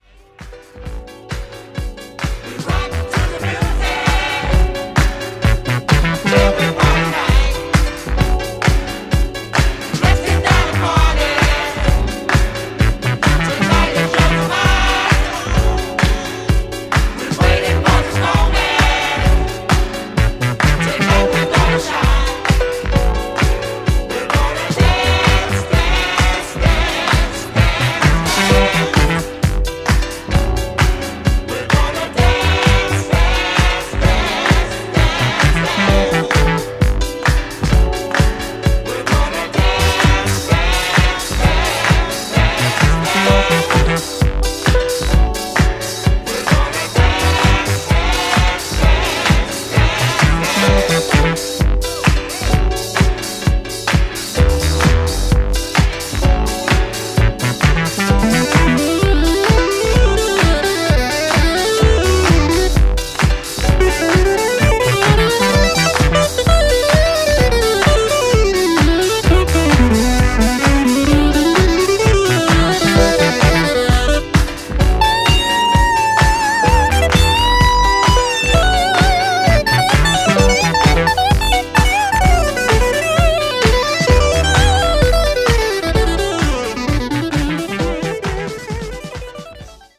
New Release Disco Classics Soul / Funk